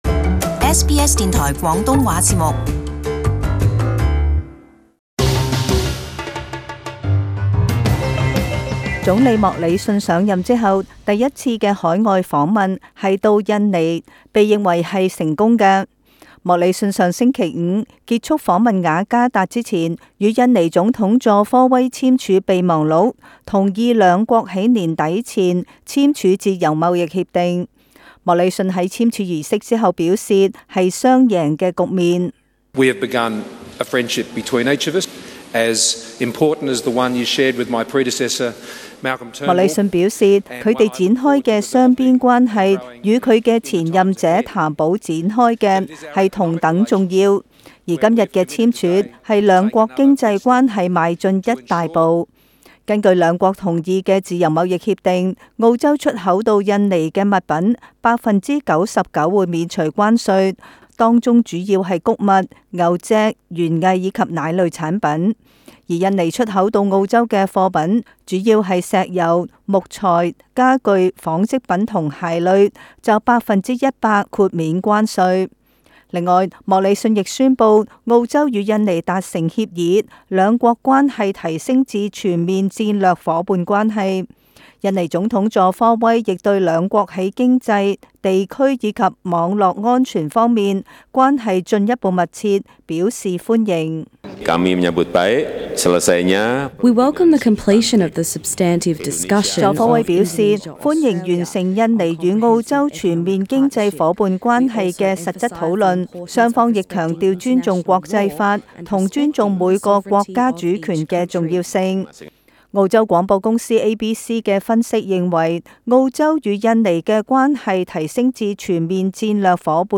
【時事報導】澳洲與印尼年底前簽署自貿協定